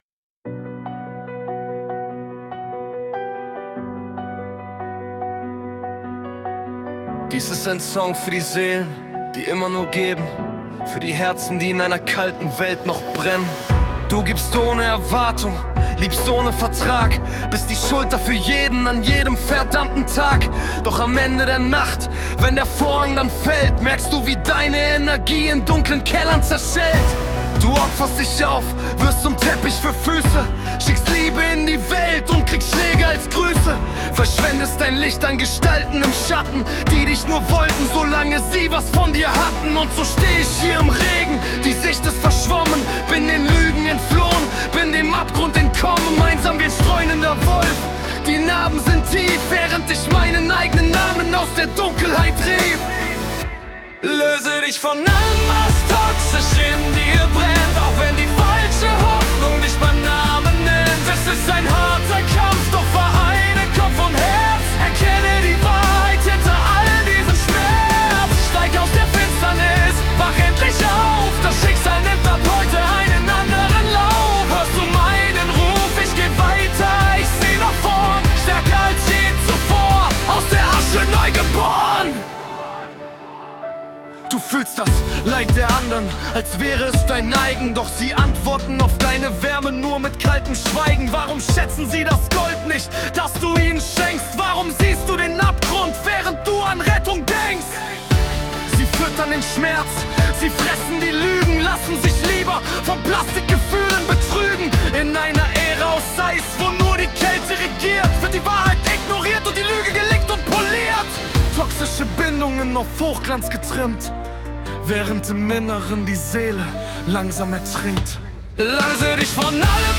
Pop Rap Version